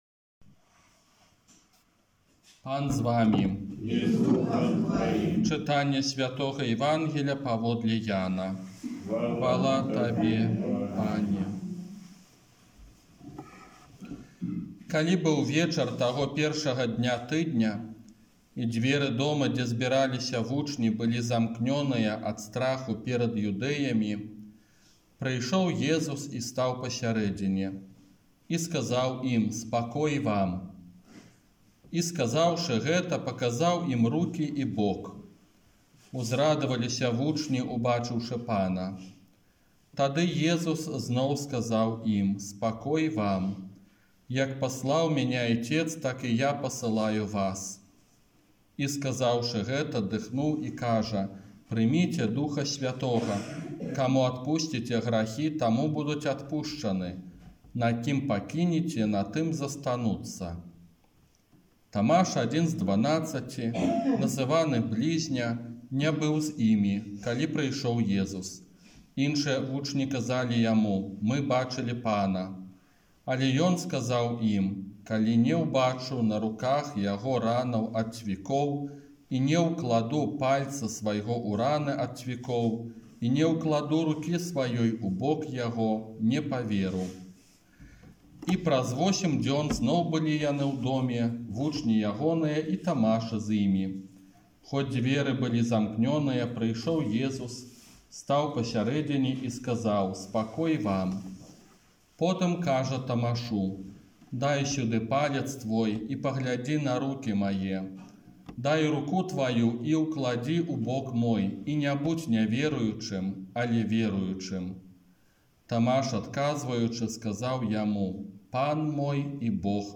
Казанне на чацвёртую велікодную нядзелю